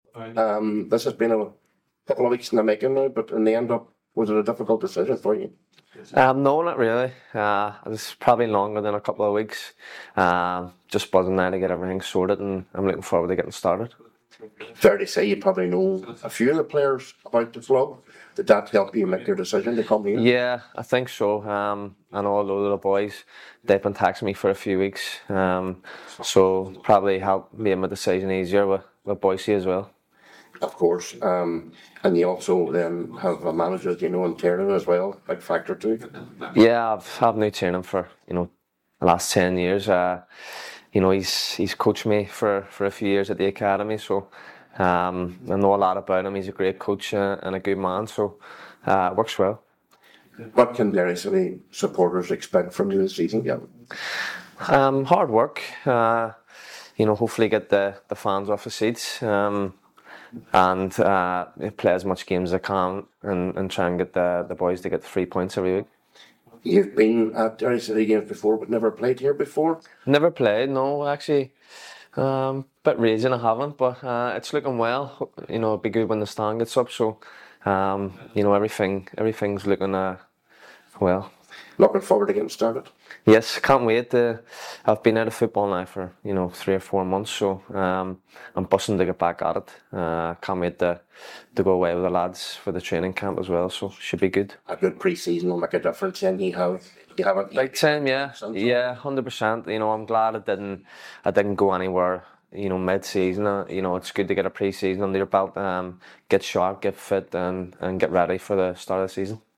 Interview Courtesy of Derry City